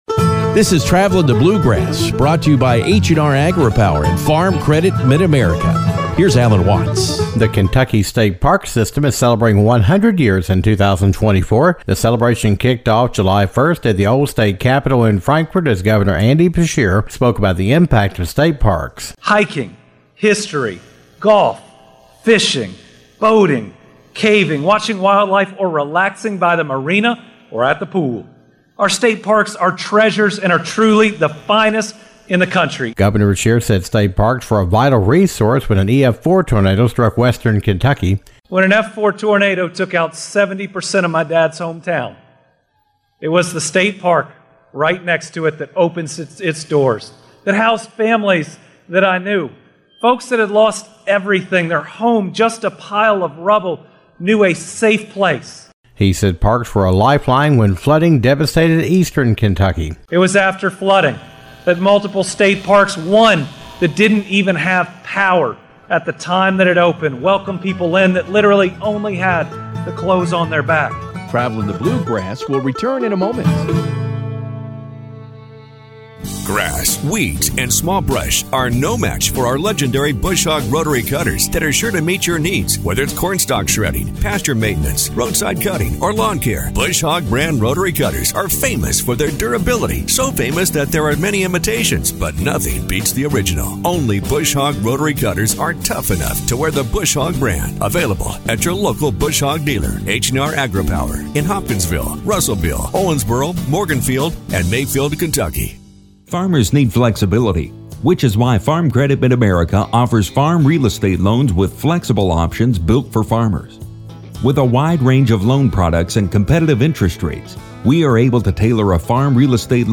Kentucky State Parks kicked off the 100 year celebration during an event in front of the old state capitol in Frankfort.